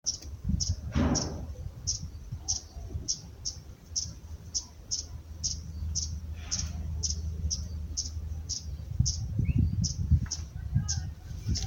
Colibrí Grande (Colibri coruscans)
Nombre en inglés: Sparkling Violetear
Fase de la vida: Adulto
País: Argentina
Provincia / Departamento: Córdoba
Condición: Silvestre
Certeza: Vocalización Grabada